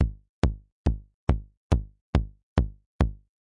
基本技术恍惚" 001
描述：由减法合成器创造的低音鼓，以140bpm的速度跳动着经典的4/4拍子的单音模式
Tag: 140BPM 基础知识 bassdrum 节拍 TECHNO 精神恍惚